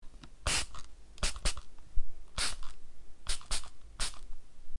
喷雾瓶 - 声音 - 淘声网 - 免费音效素材资源|视频游戏配乐下载
以不同的速度和节奏喷洒内部有液体的喷雾瓶。 使用Tascam DR4048kHz 24bit录制